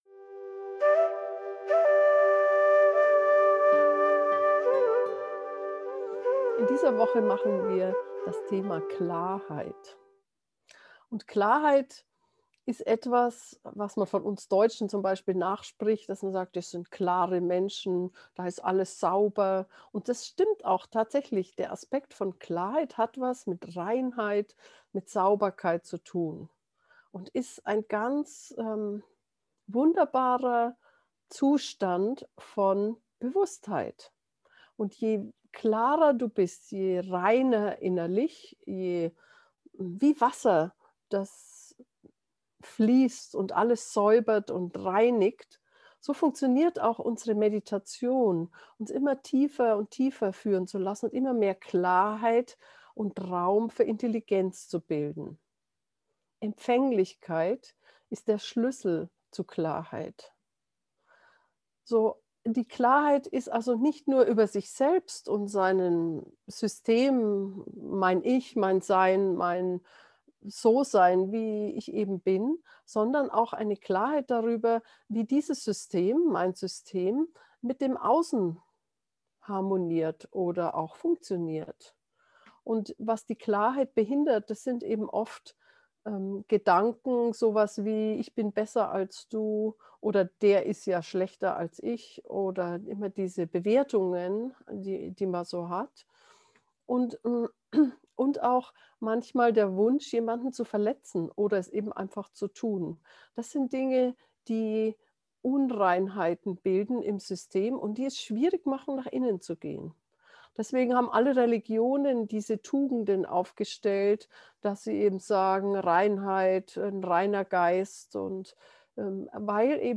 klarheit-brabbeln-gefuehrte-meditation.mp3